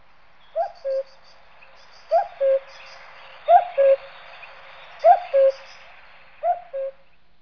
Tous les jours sans exceptions, je suis accompagné par
le chant du coucou. coucou2.jpg (86041 octets)
coucou.wav